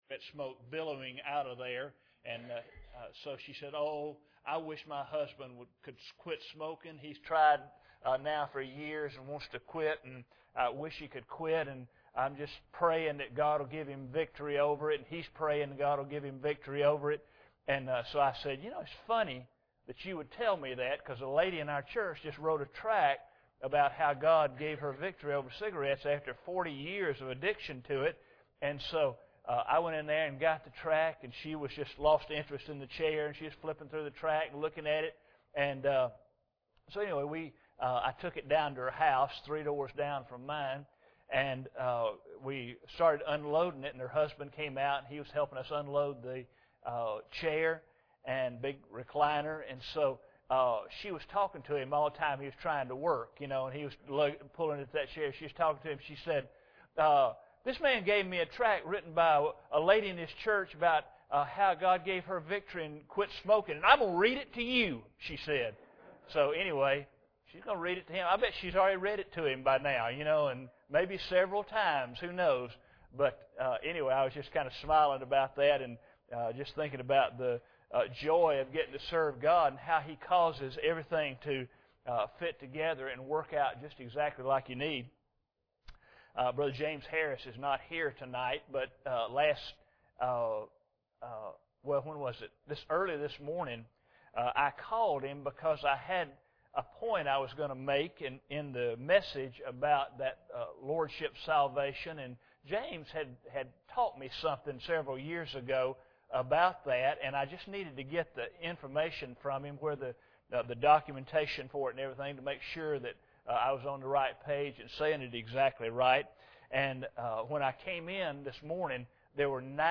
Members of the church share testimonies of how God has worked in their lives.
October 21, 2012 Testimonies of Humility Speaker: CCBC Members Series: General Service Type: Sunday Evening Preacher: CCBC Members | Series: General Members of the church share testimonies of how God has worked in their lives.